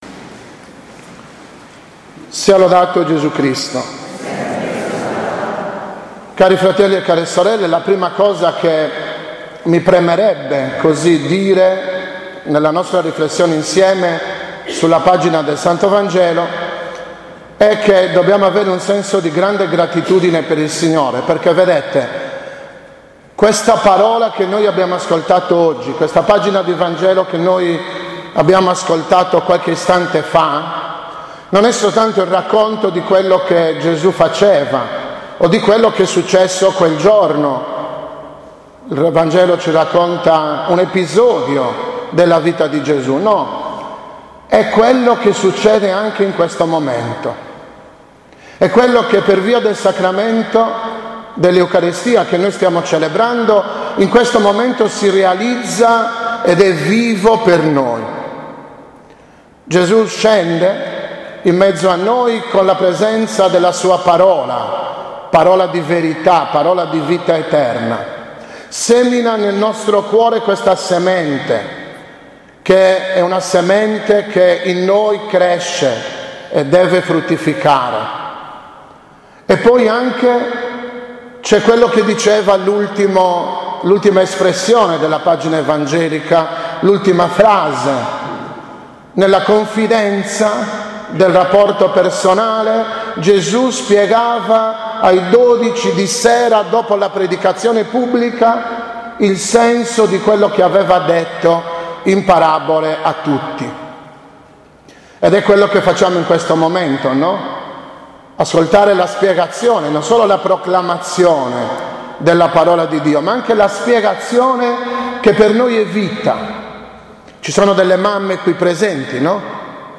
29.06.2015 - FESTA DEI SANTI PATRONI PIETRO E PAOLO CELEBRATA DA MONS. ARCIVESCOVO ARRIGO MIGLIO - Riti di Introduzione e Liturgia della Parola